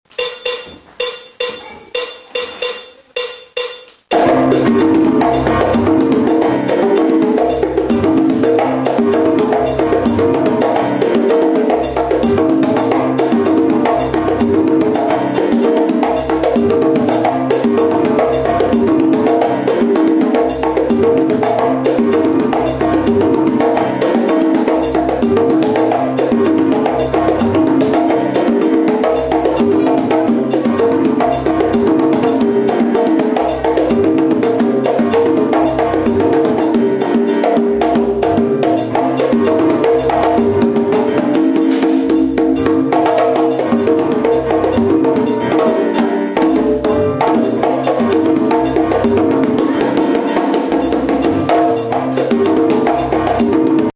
The Mozambique is a style of drumming that was developed in Cuba by Pedro Izquierdo called "Pello El Afrokan" in the early 1960's.
Is a style of Cuban carnaval music traditionally played on percussion intruments only.